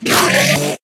Minecraft Version Minecraft Version 25w18a Latest Release | Latest Snapshot 25w18a / assets / minecraft / sounds / mob / endermen / scream4.ogg Compare With Compare With Latest Release | Latest Snapshot
scream4.ogg